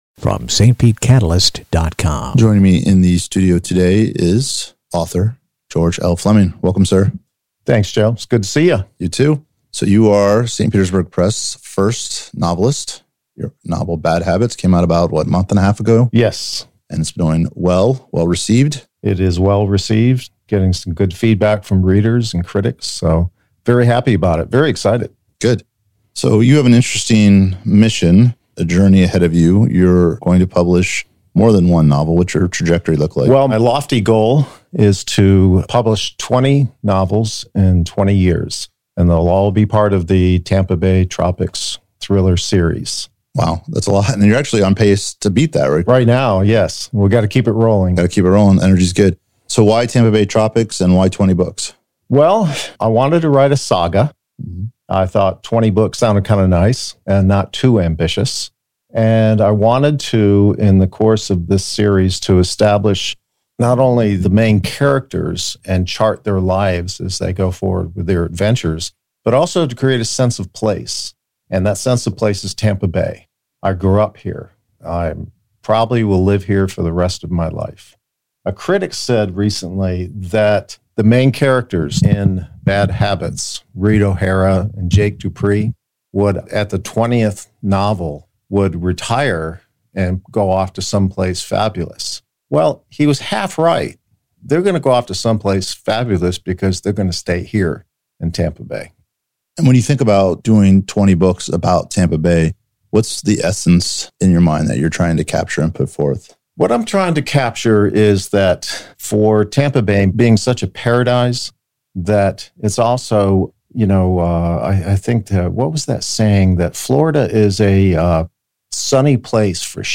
St. Pete Catalyst Interview